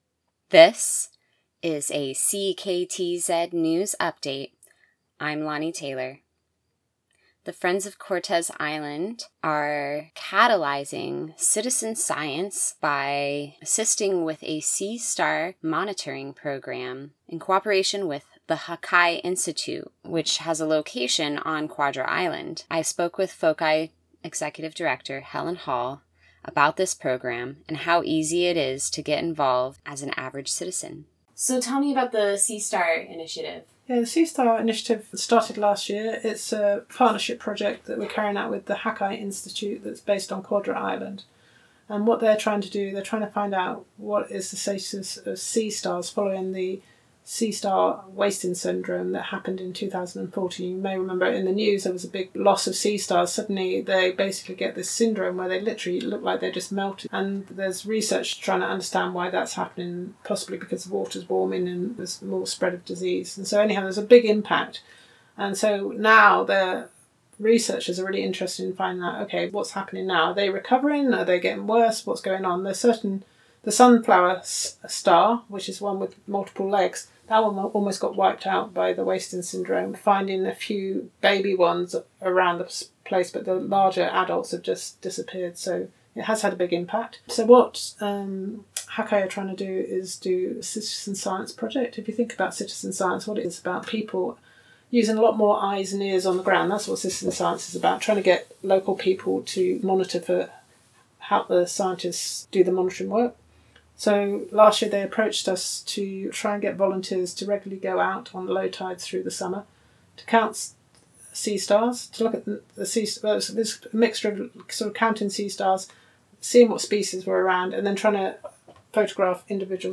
CKTZ-News-Sea-Star-Initiative-with-FOCI.mp3